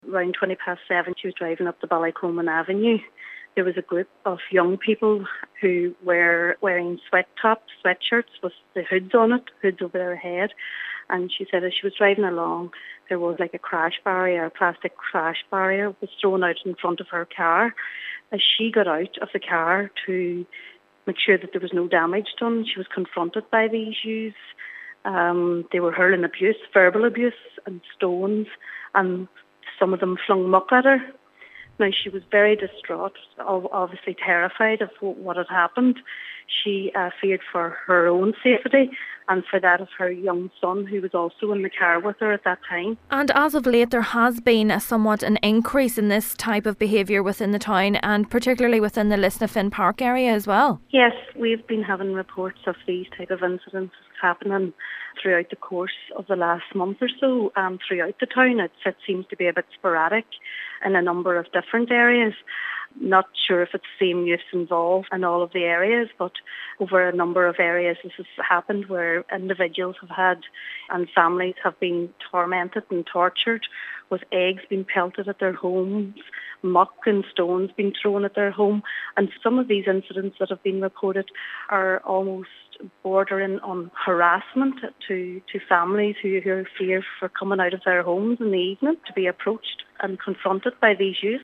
West Tyrone MLA Michaela Boyle says people are effectively living in fear: